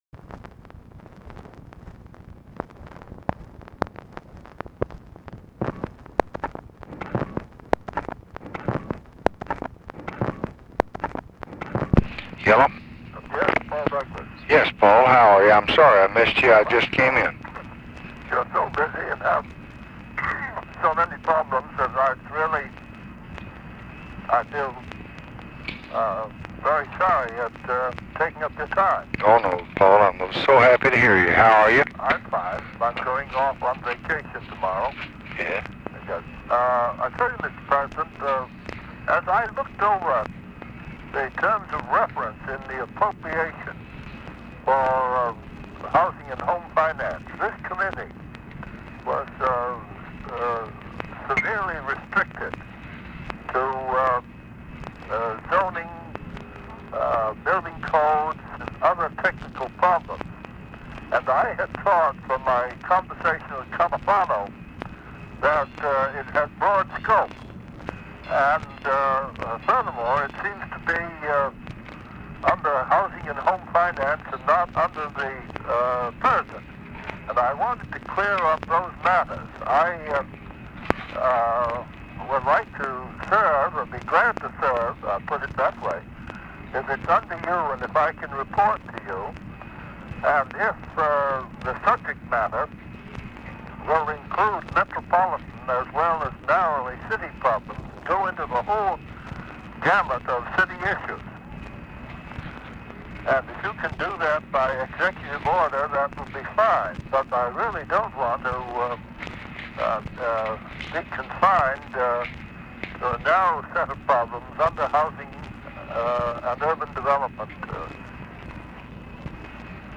Conversation with PAUL DOUGLAS, December 23, 1966
Secret White House Tapes